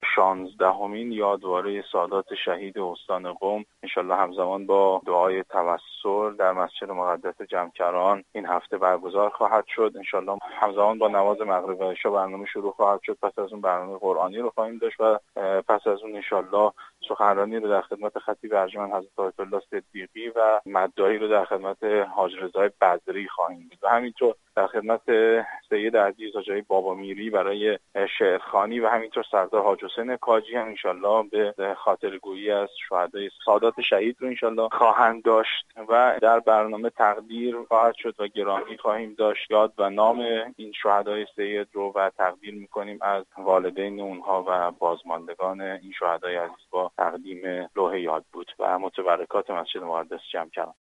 در گفتگوی تلفنی